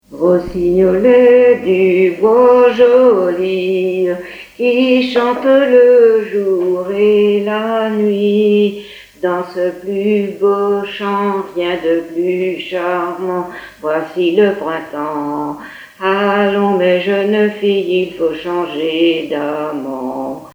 Origine : Haute-Bretagne (pays de Châteaubriant) Année de l'arrangement : 2017